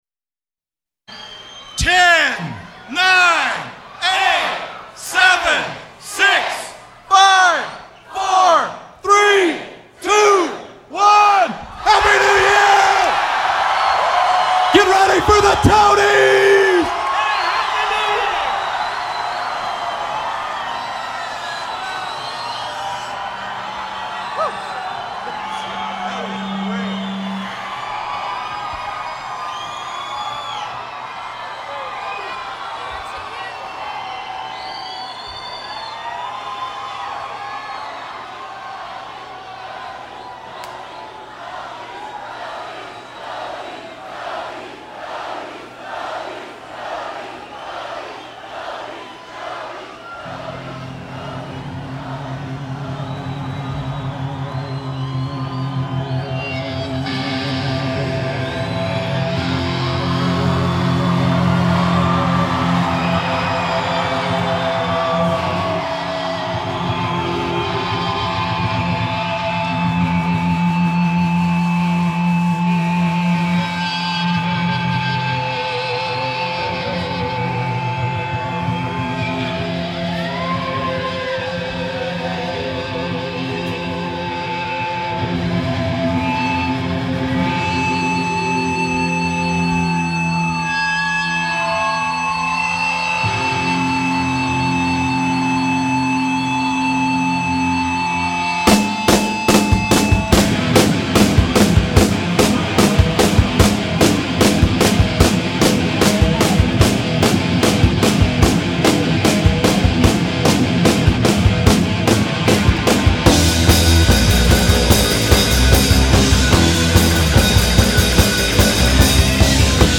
high-quality live performance recording
ultra-rare hypnotic
Remastered 2020
lead singer/ rhythm guitarist
bass player